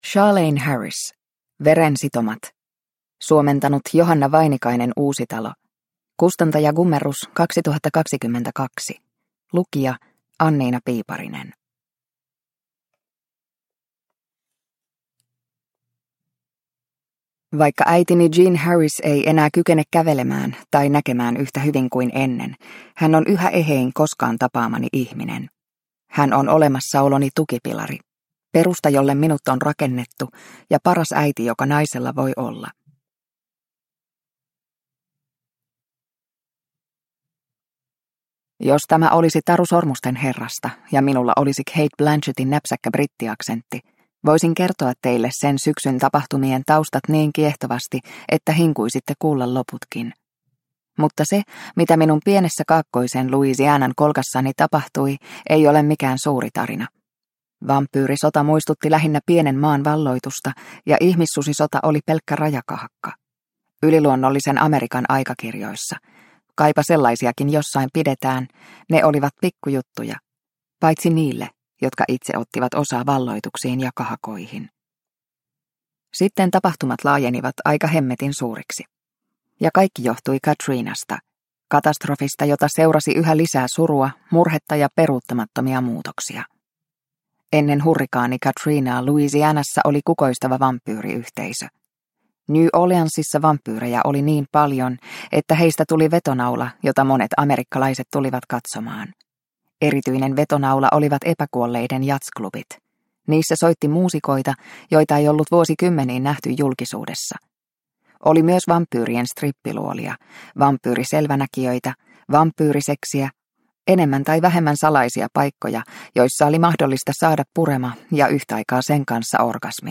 Veren sitomat – Ljudbok – Laddas ner